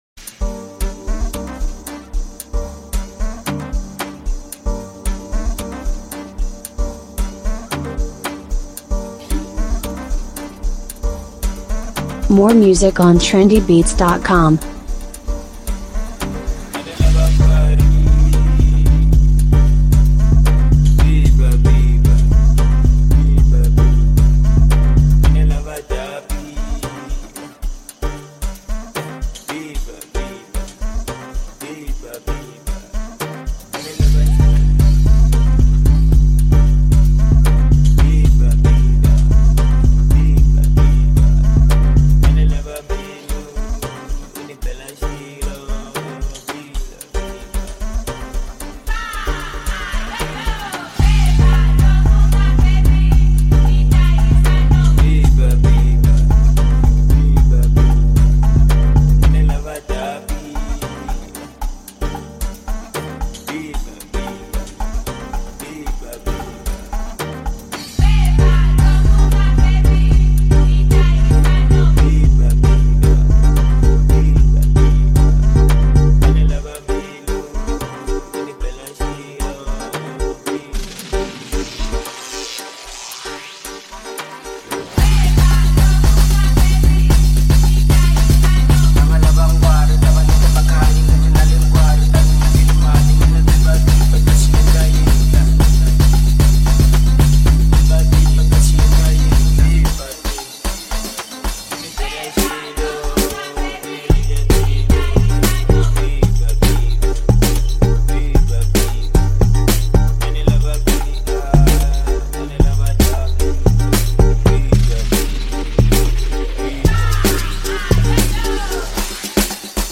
a South African DJ and performer
energising song